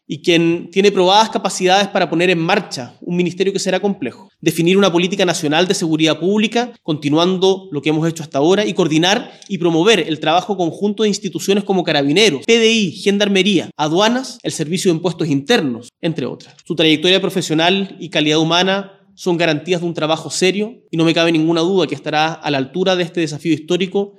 Por su parte, en la cadena nacional, que fue grabada a eso de las 16:00 horas, y emitida a las 20:50, el Presidente Boric destacó que con la puesta en marcha del ministerio “tendremos un Estado más fuerte”, para fortalecer la respuesta al crimen y la prevención de la violencia.